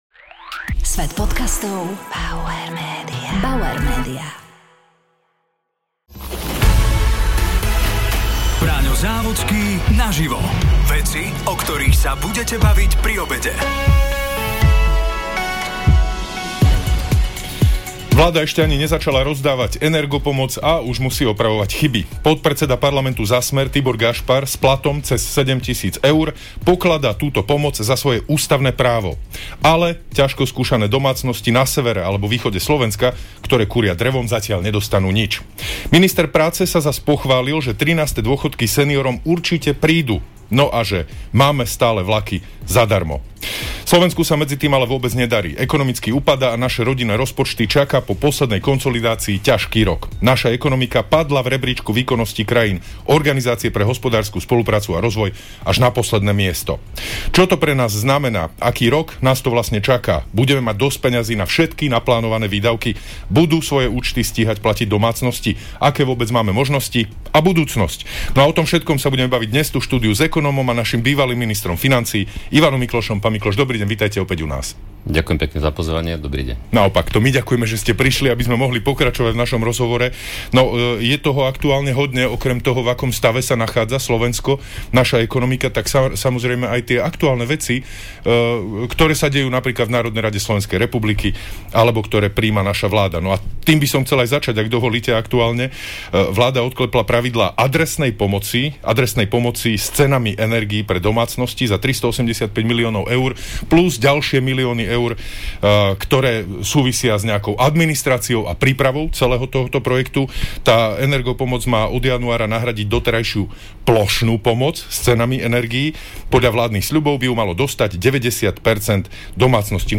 Braňo Závodský sa rozprával s ekonómom a bývalým ministrom financií Ivanom Miklošom.